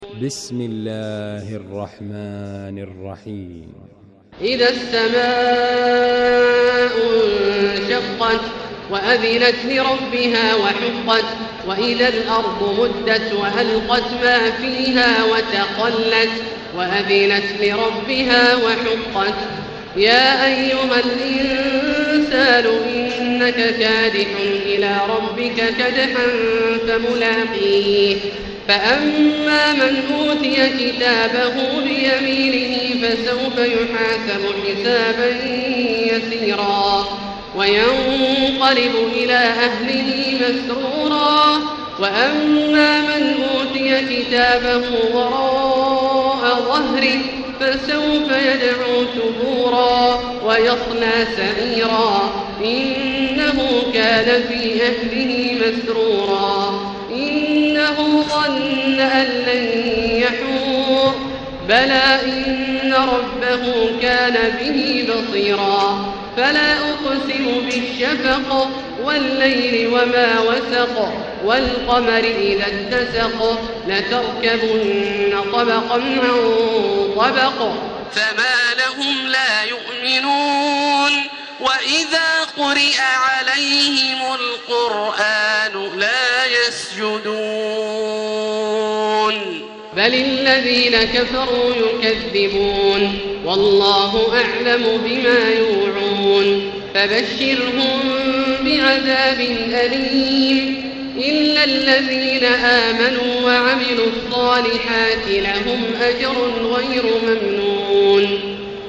المكان: المسجد الحرام الشيخ: فضيلة الشيخ عبدالله الجهني فضيلة الشيخ عبدالله الجهني الانشقاق